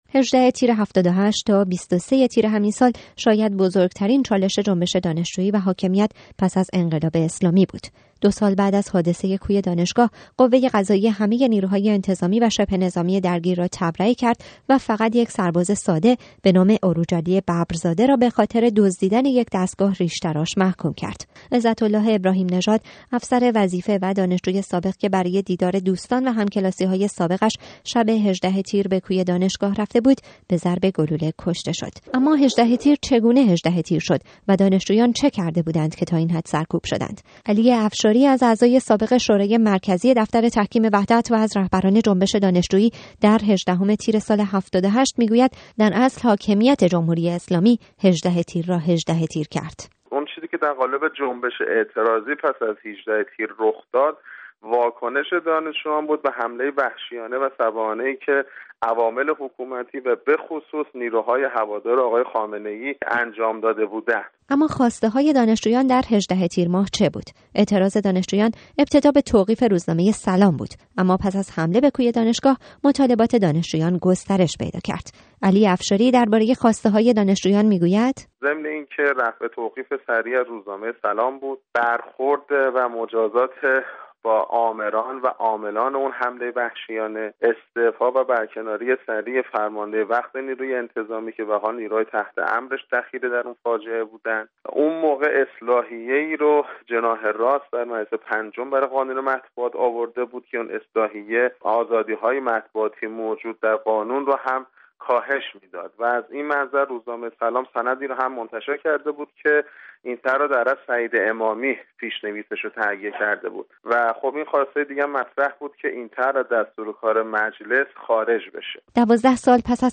گزارش رادیویی